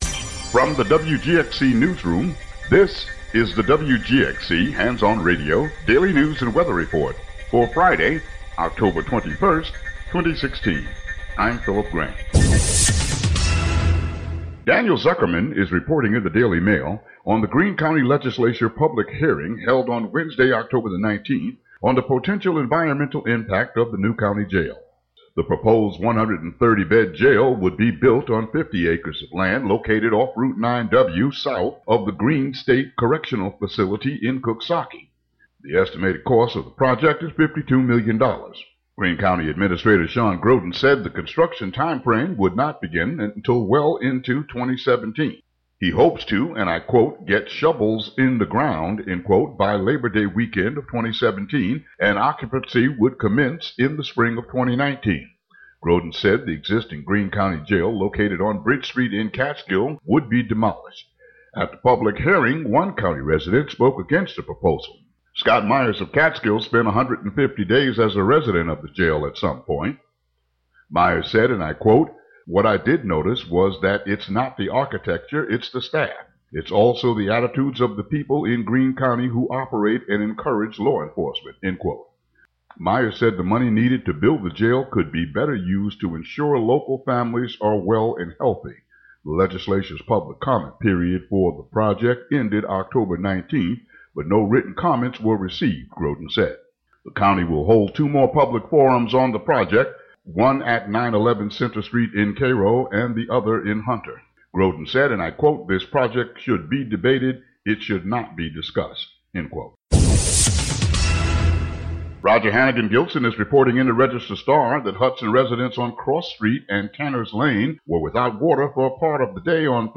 WGXC daily headlines and weather.